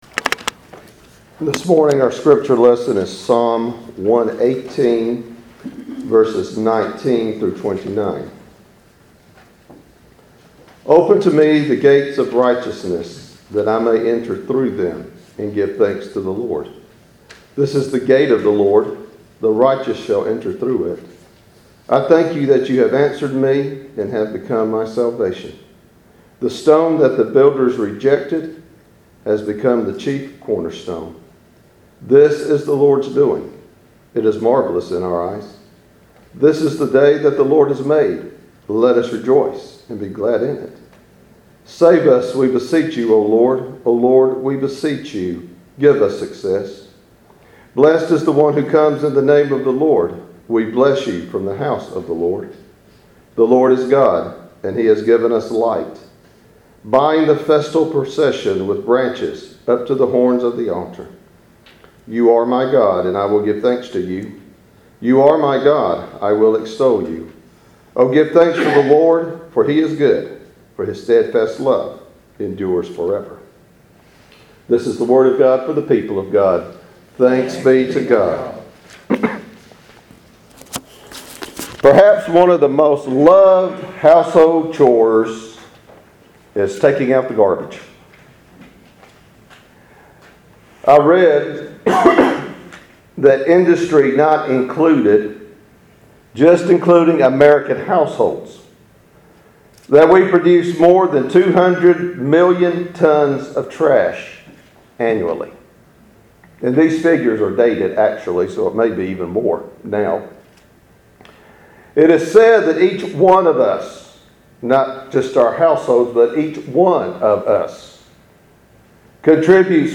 Walker Church SERMONS